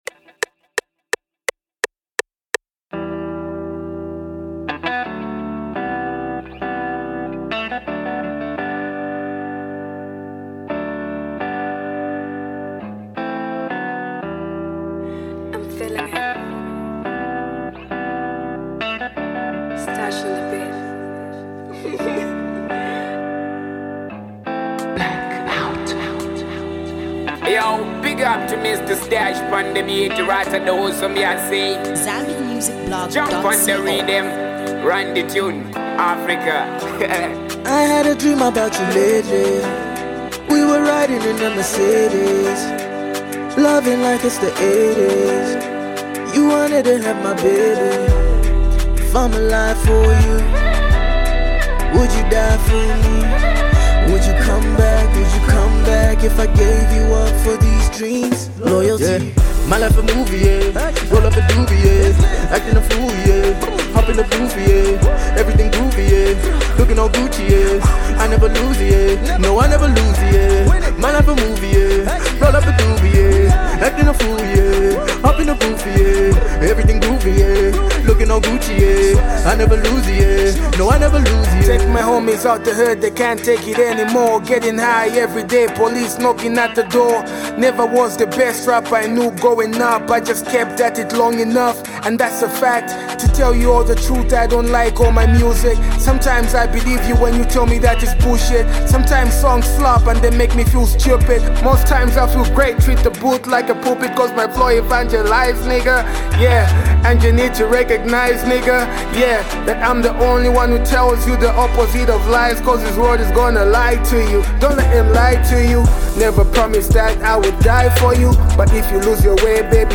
This offering is definitely a hip hop masterpiece.